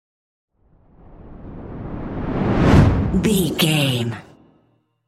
Whoosh fire ball
Sound Effects
Atonal
dark
intense
whoosh